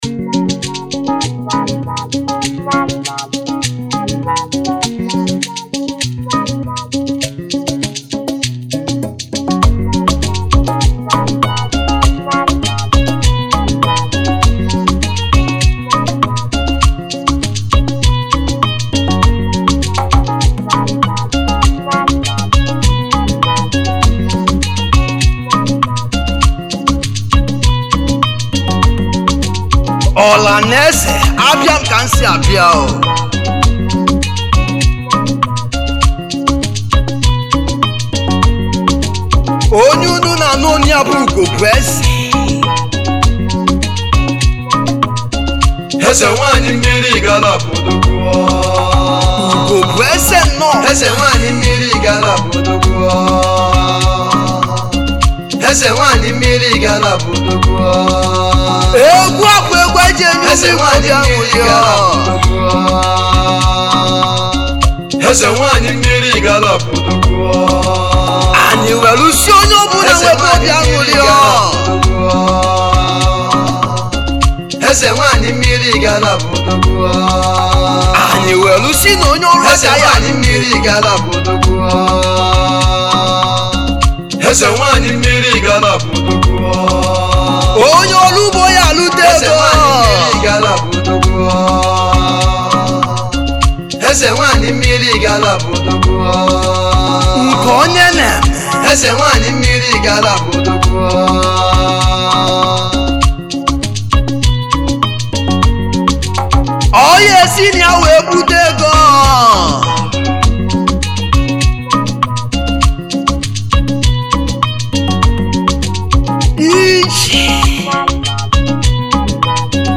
Highlife Traditional Free